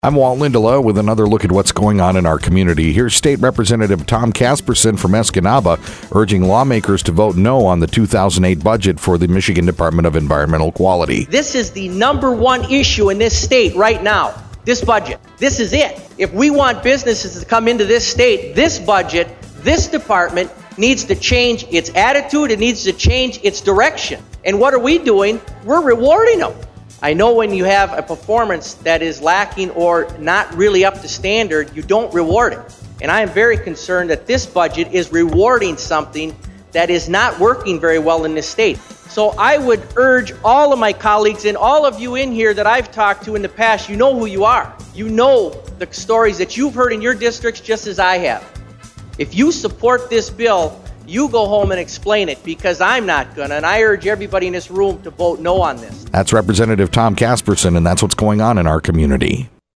Click HERE To Listen To Interview as Mp3 Click To Submit Press Releases, News, Calendar Items, and Community Events to mediaBrew radio stations WFXD, WKQS, WRUP, GTO, Fox Sport...